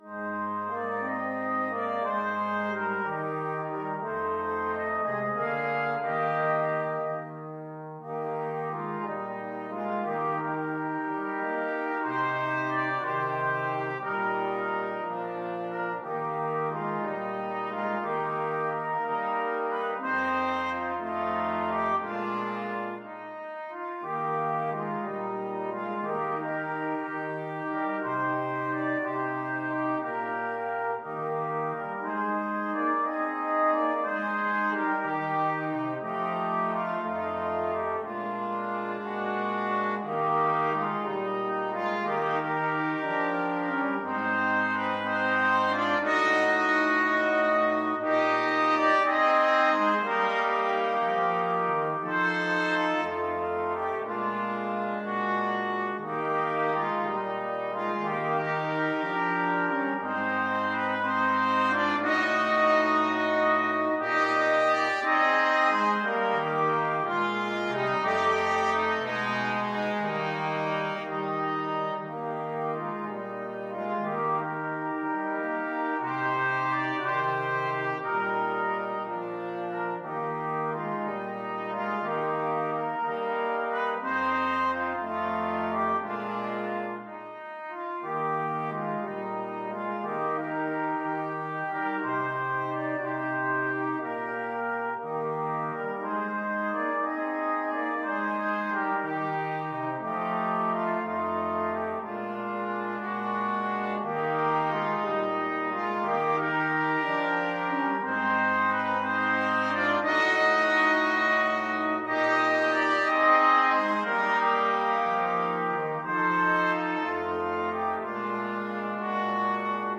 Trumpet 1Trumpet 2French HornTrombone
6/8 (View more 6/8 Music)
Gently and with expression . = c. 60
Traditional (View more Traditional Brass Quartet Music)
Rock and pop (View more Rock and pop Brass Quartet Music)